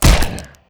Damage7.ogg